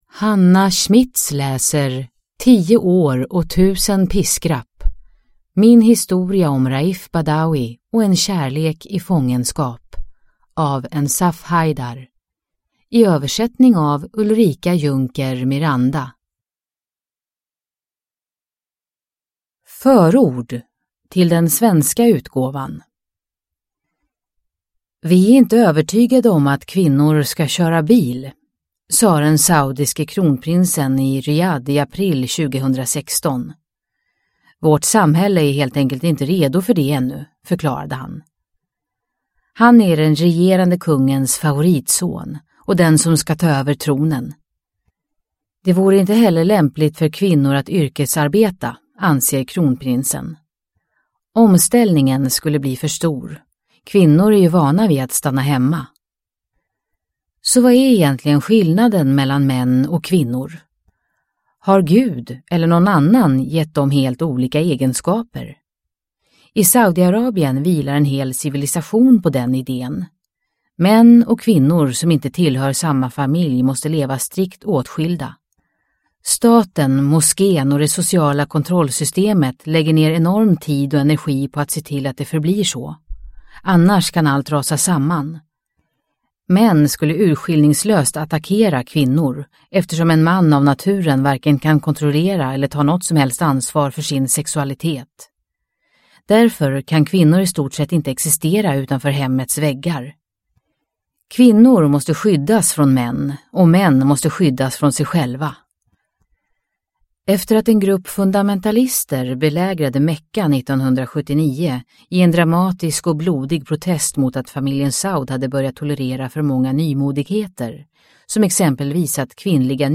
10 år och 1000 piskrapp : min historia om Raif Badawi och en kärlek i fångenskap – Ljudbok – Laddas ner